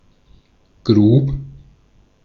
Ääntäminen